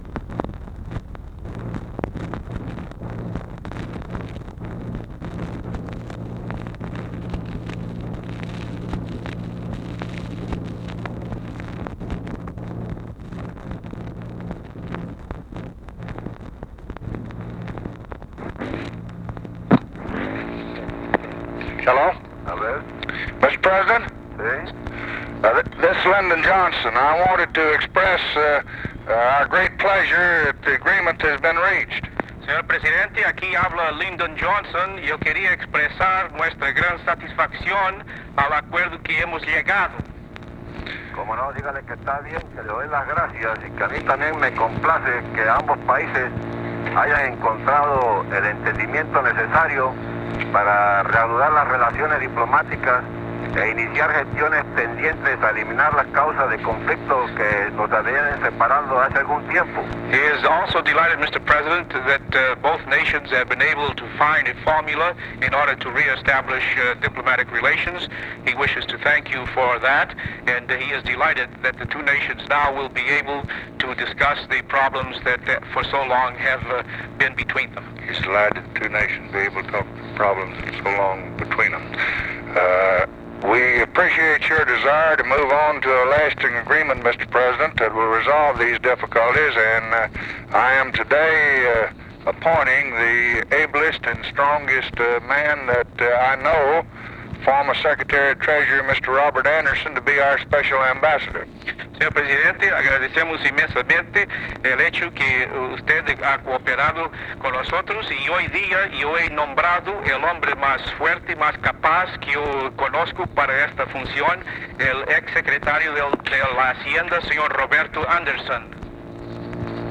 Conversation with ROBERTO F. CHIARI, April 3, 1964
Secret White House Tapes